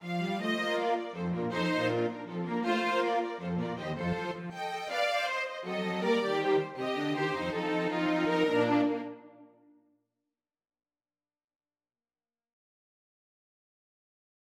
어니스트 워커(Ernest Walker)는 스케르초(Scherzo)가 3/4 박자이지만, 6/8 박자처럼 들리도록 하는 교차 리듬이 지속적으로 나타난다고 설명한다.[8]
베토벤 현악사중주 6번 작품 18번의 스케르초, 바이올린과 첼로만